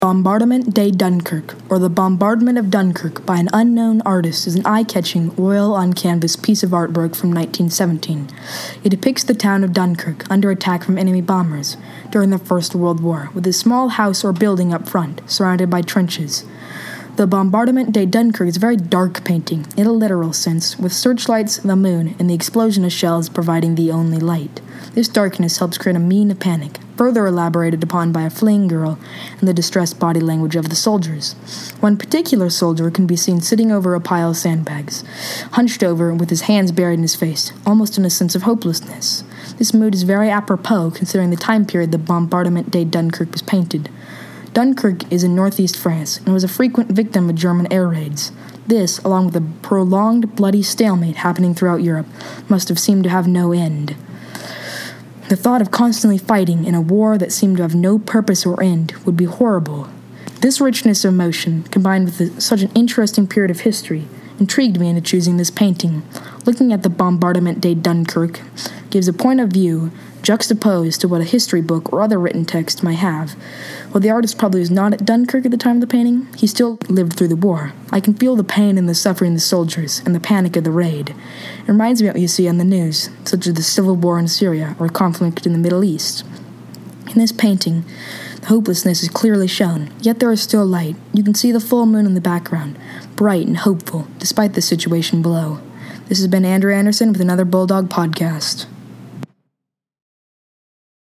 Audio Tour – Bulldog Podcast